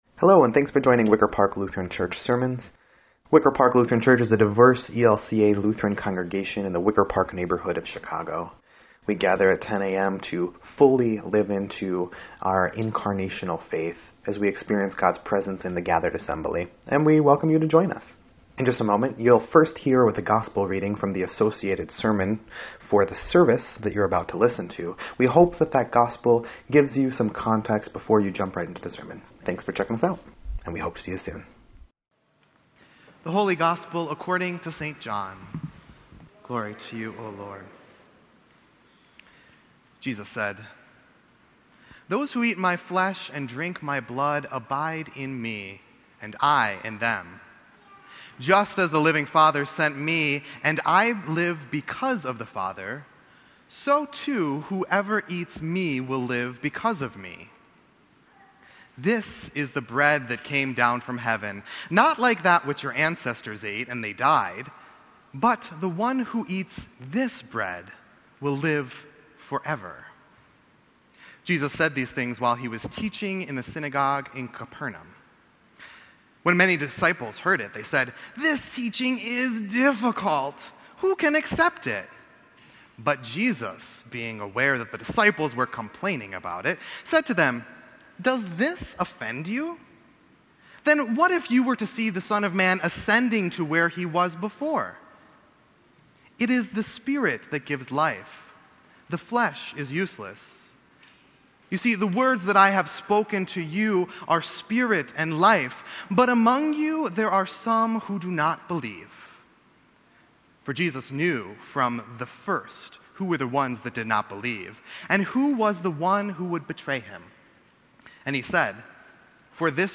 EDIT_Sermon_8_26_18.mp3